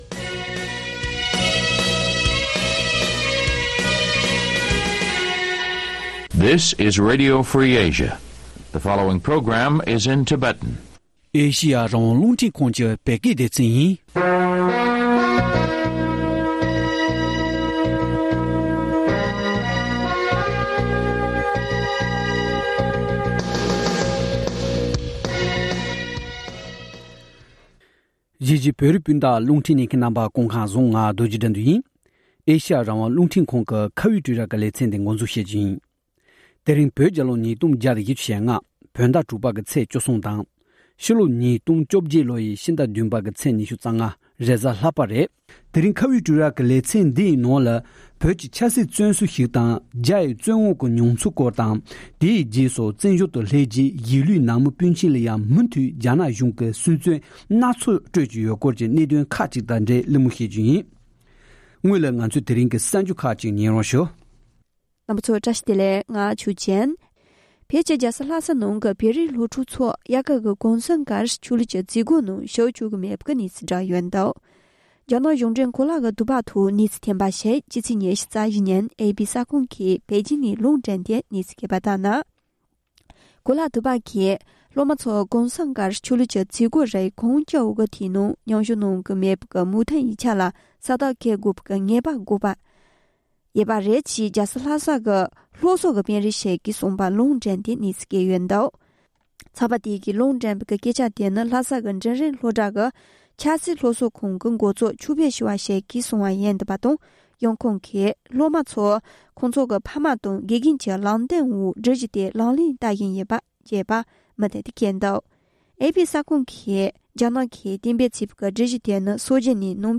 བཅའ་འདྲི་བྱས་པ་ཞིག་ན་ལ་ཉན་རོགས་ཞུ༎